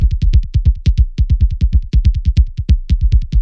percs stuff " 嘈杂的Perc延迟 - 声音 - 淘声网 - 免费音效素材资源|视频游戏配乐下载